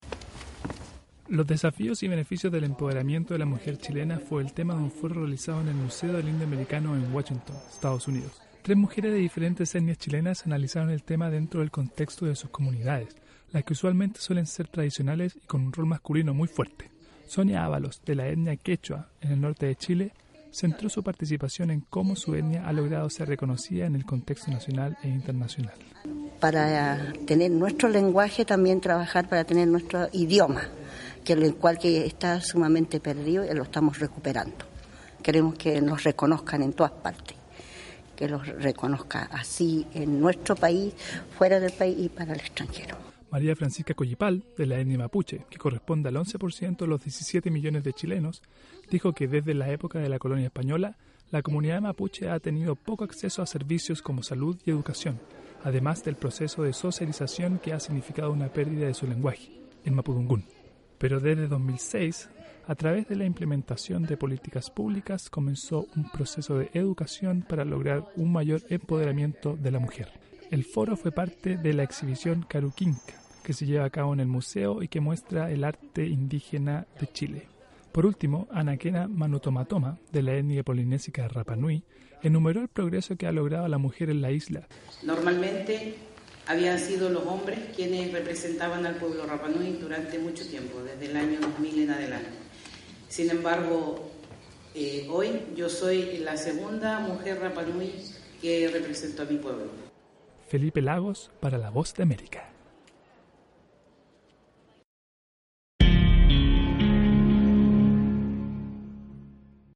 Tres representantes indígenas de Chile hablaron sobre los logros y desafíos que enfrentan las mujeres de comunidades originarias en un foro en el Museo del Indio Americano en Washington, EE.UU. Duración: 1:42